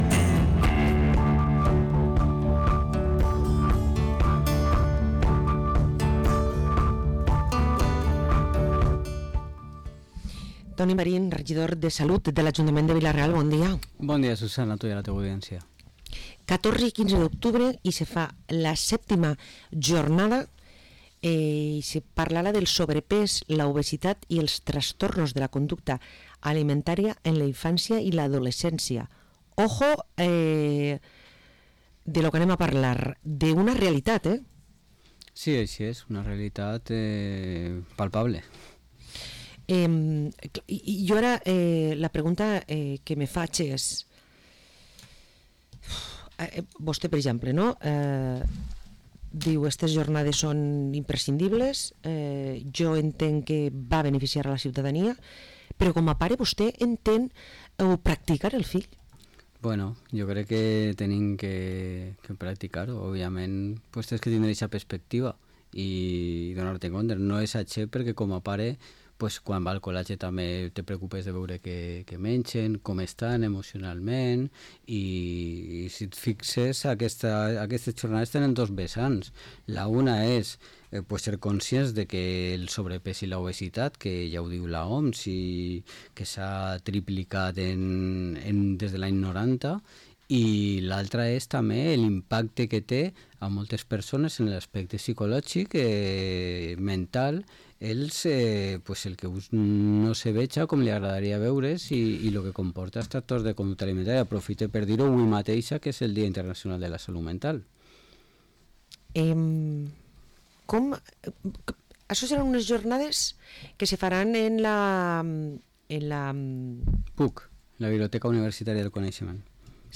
Parlem amb el regidor Toni Marín de les «VII Jornades Ruia»